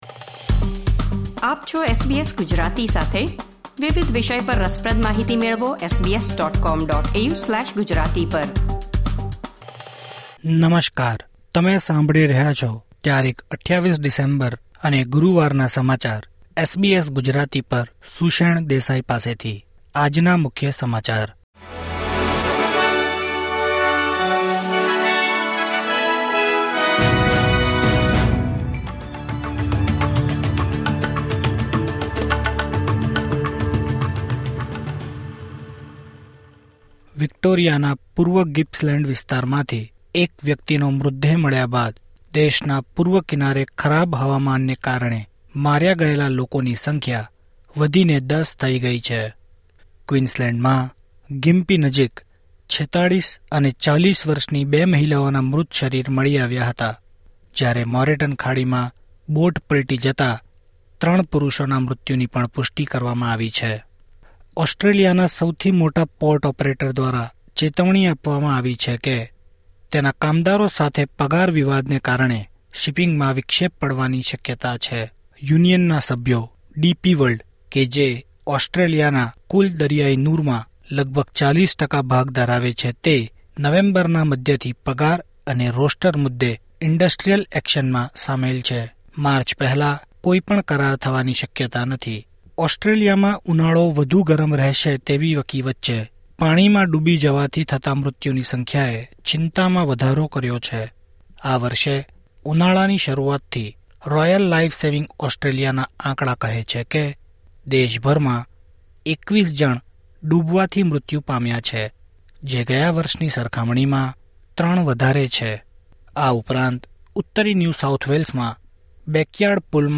SBS Gujarati News Bulletin 28 December 2023